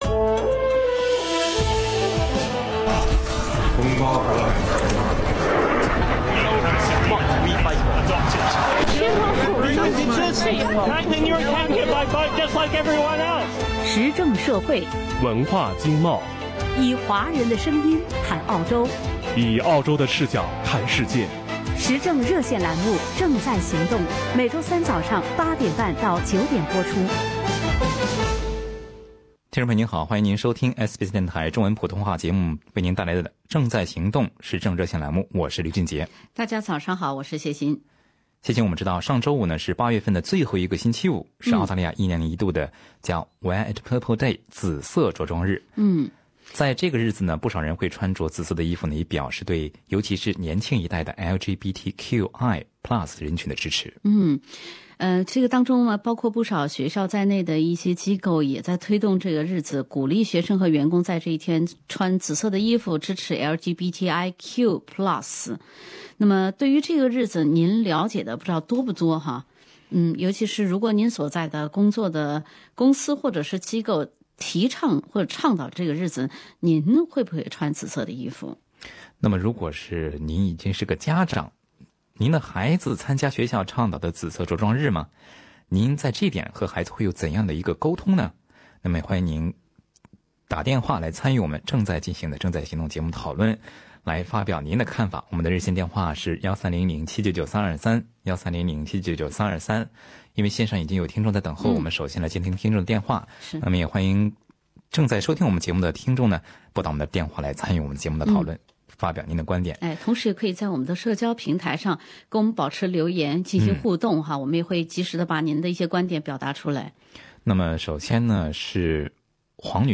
不少听众朋友在本期《正在行动》热线节目中表达了自己的看法。